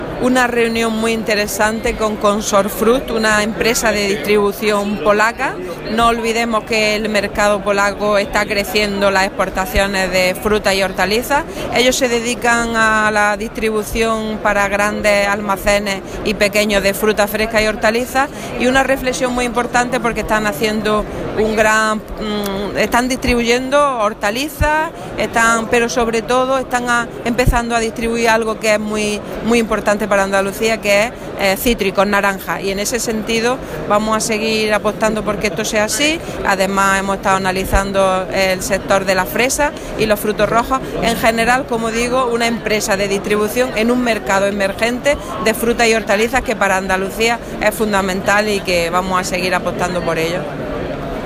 Declaraciones de Carmen Ortiz sobre reunión con representantes de Consorfrut Polska y exportaciones hortofrutícolas a Polonia